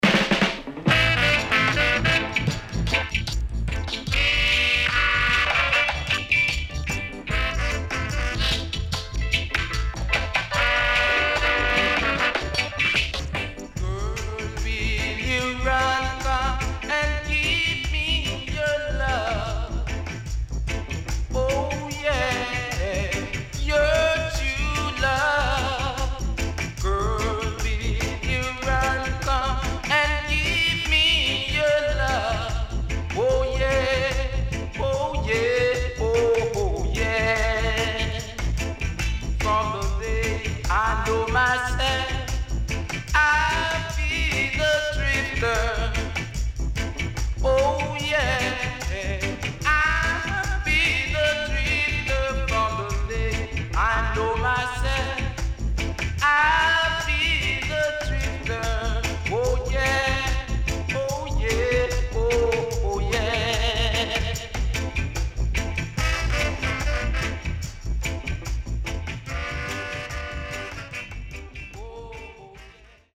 A:Vocal→Deejay Cut.B:Killer Horn Cut→Dubwise→Bongo Cut